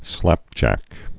(slăpjăk)